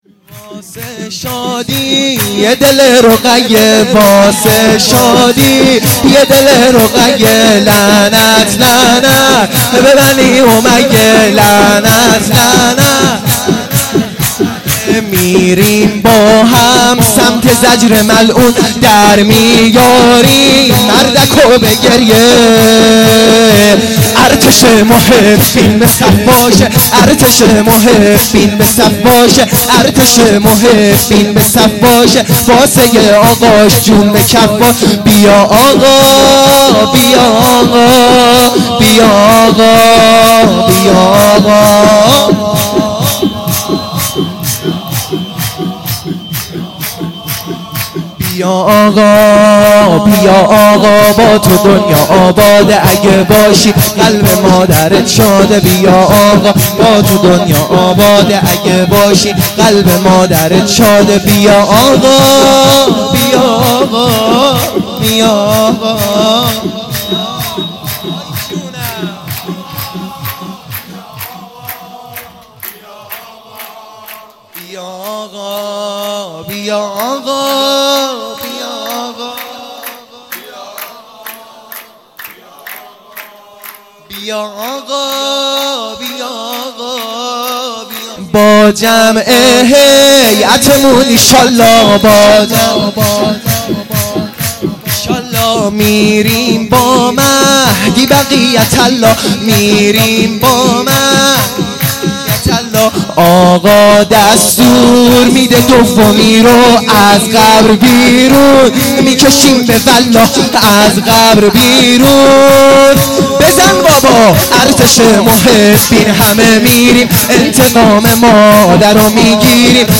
سرود
میلاد حضرت رقیه